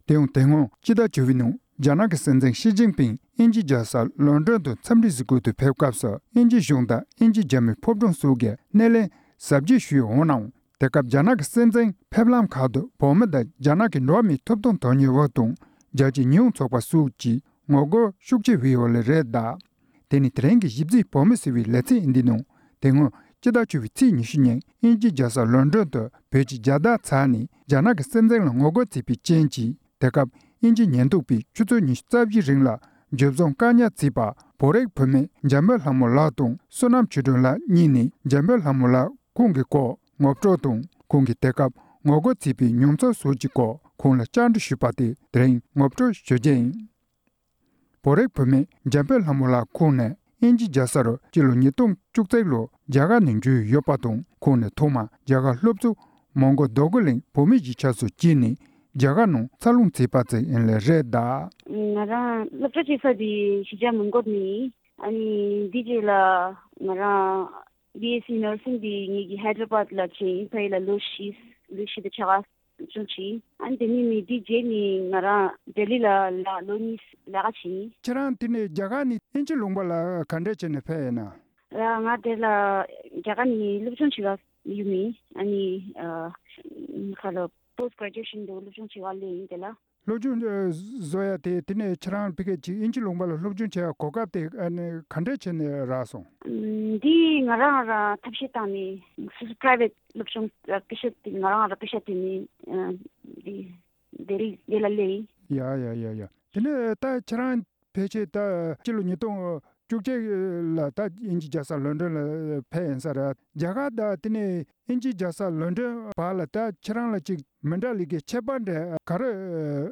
སྒྲ་ལྡན་གསར་འགྱུར། སྒྲ་ཕབ་ལེན།